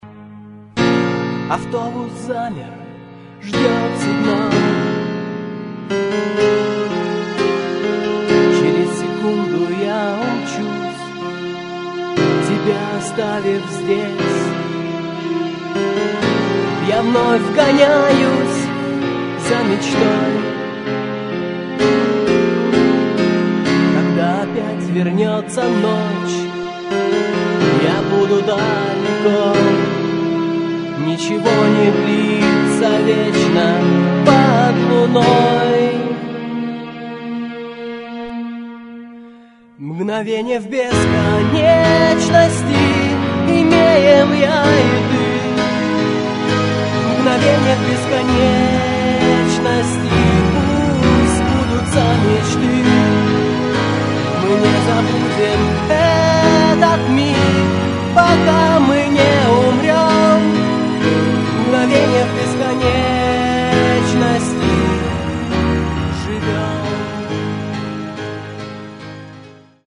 Клавиши, вокал
фрагмент (410 k) - mono, 48 kbps, 44 kHz